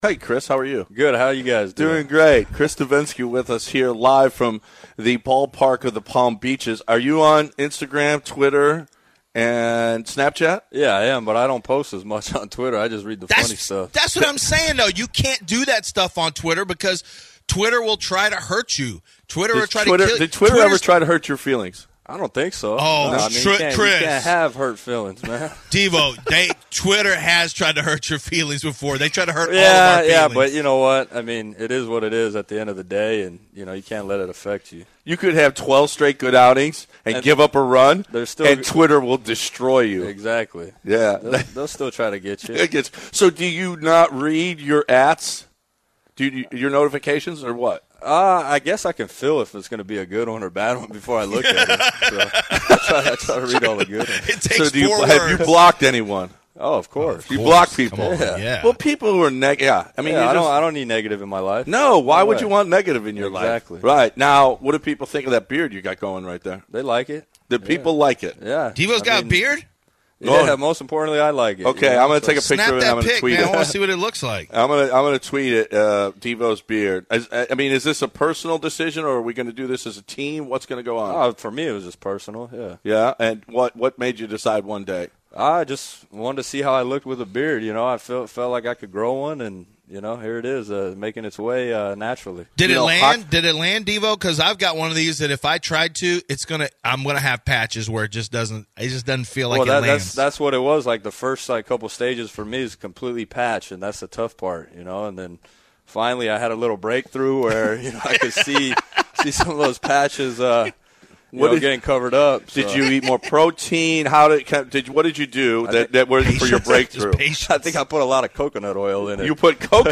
Chris Devenski interview: Discussed Devo’s new beard.